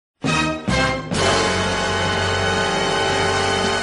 Suspense.duSuspense.dundundundunndunduun